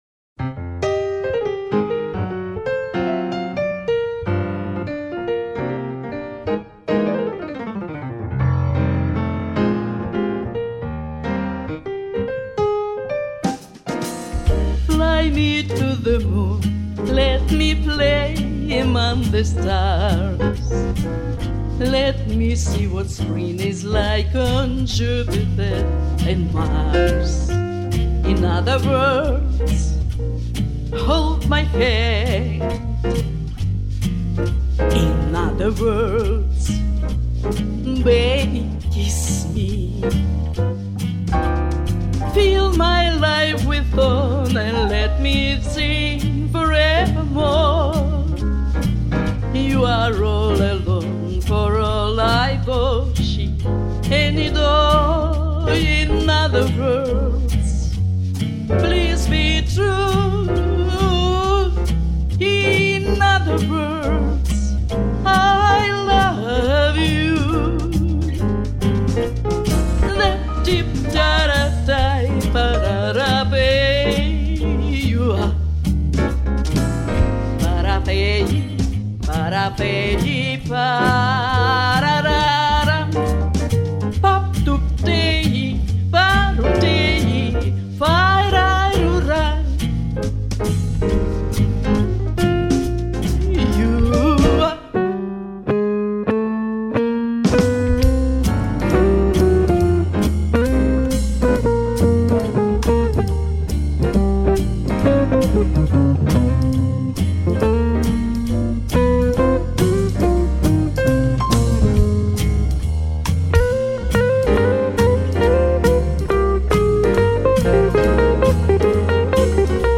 минус интересный.. klass rozochka